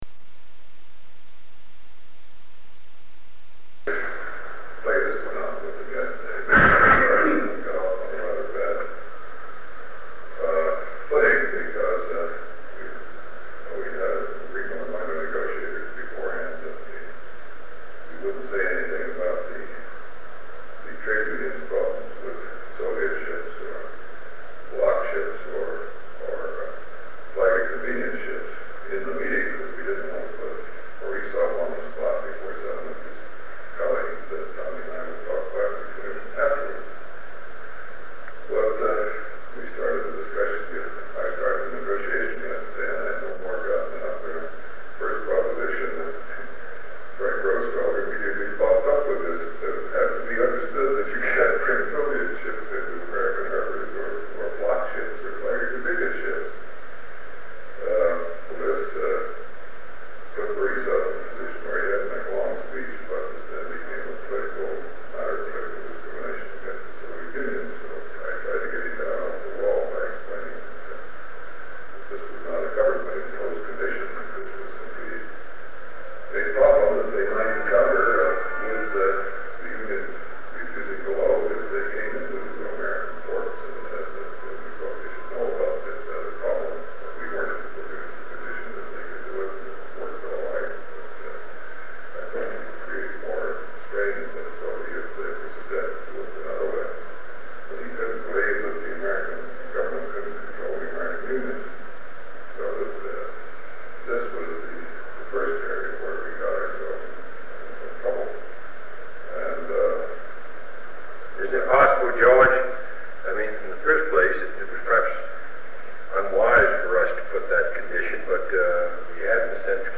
Sound recording of a meeting held on November 2, 1963, between President John F. Kennedy, Under Secretary of State George Ball, Special Assistant to the President for National Security Affairs McGeorge Bundy, and Under Secretary of State W. Averell Harriman. They discuss the delivery of wheat on American ships to the Soviet Union, alternatives, and related discussions with American unions.
Secret White House Tapes | John F. Kennedy Presidency Meetings: Tape 119/A55.